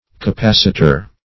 capacitor \ca*pac"i*tor\ (k[.a]*p[a^]s"[i^]*t[~e]r), n.